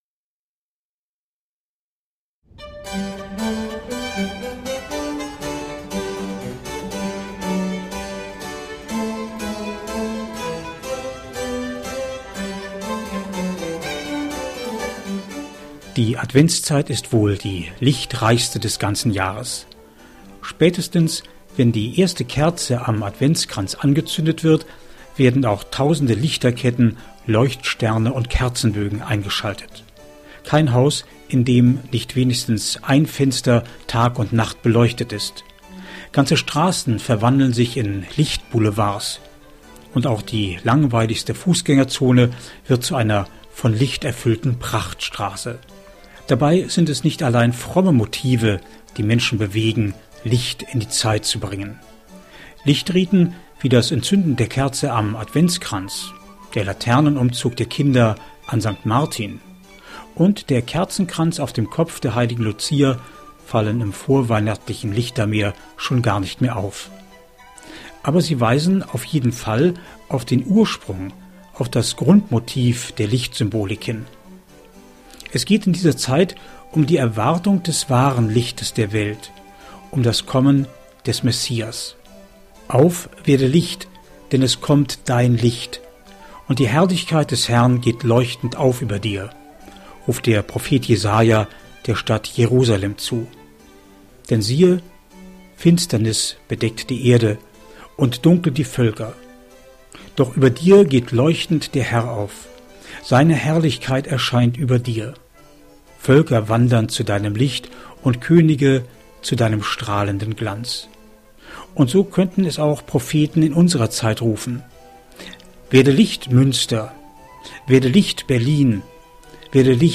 MEDITATION